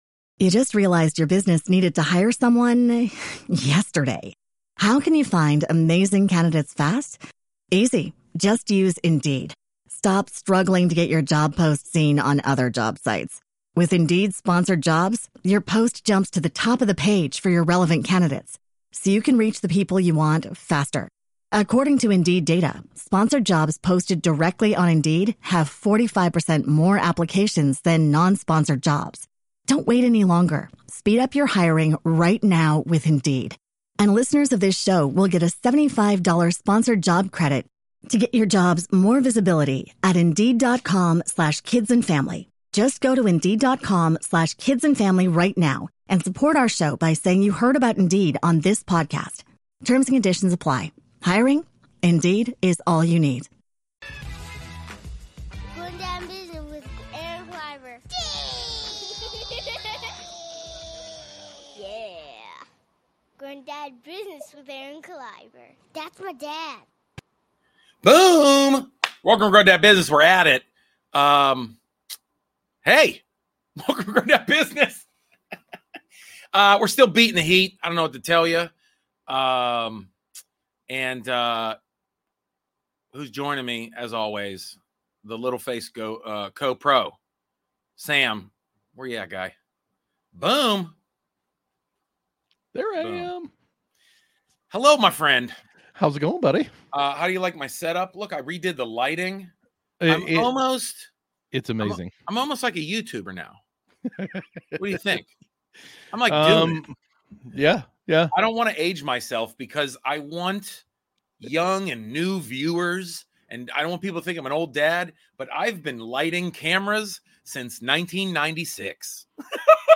It’s comedy, it’s a podcast, it’s a vibe.